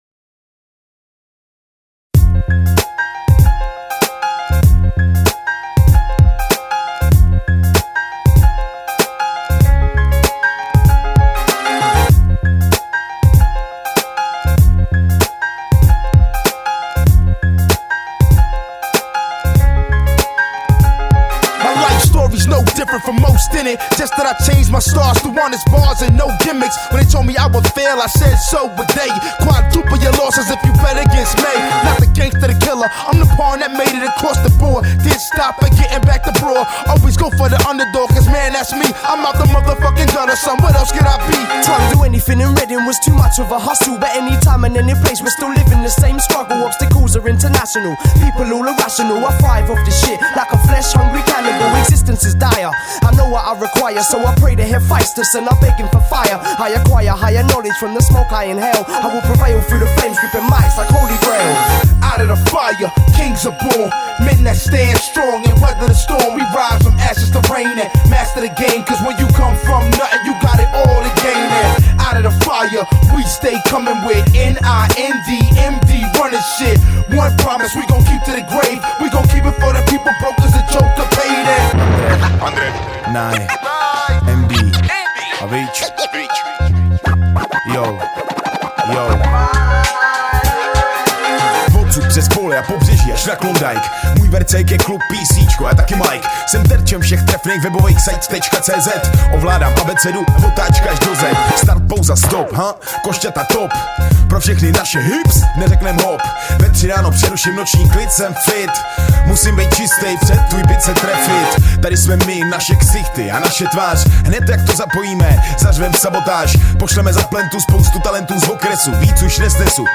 7 Styl: Hip-Hop Rok